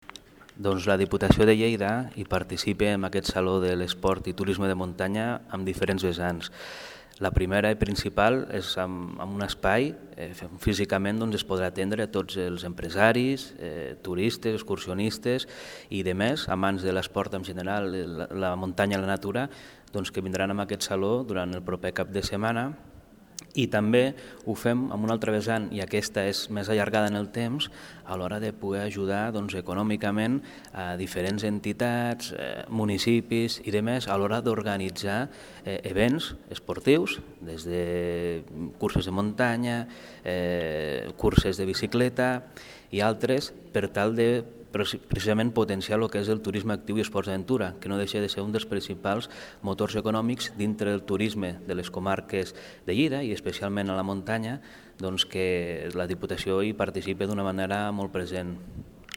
El diputat de la Diputació de Lleida i vicepresident del Patronat de Turisme, Gerard Sabarich, ha assistit a la roda de premsa de presentació de la 2a edició del Saló de l’Esport i Turisme de Muntanya, que se celebrarà del 6 al 8 de febrer a Fira de Lleida
Declaracions-Sr.-Gerard-Sabarich.mp3